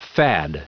Prononciation du mot fad en anglais (fichier audio)
Prononciation du mot : fad